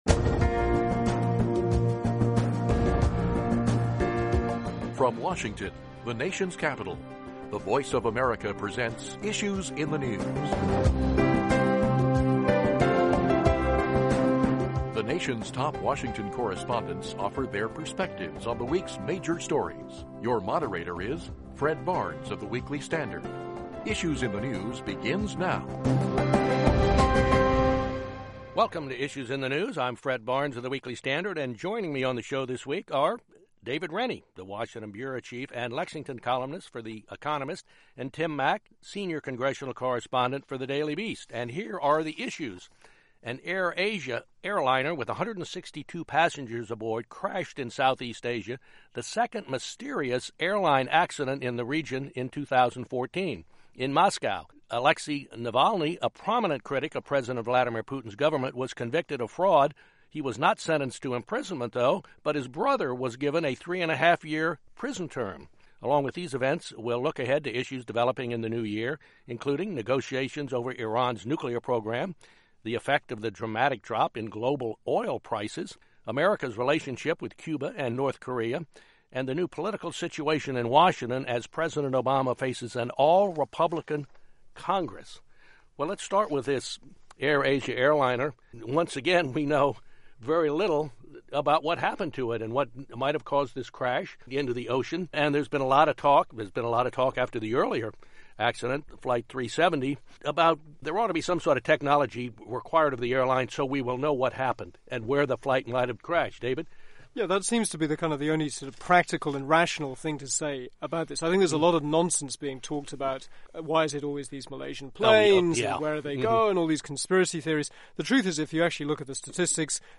Moderator Fred Barnes, Executive Editor of the Weekly Standard